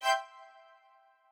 strings5_49.ogg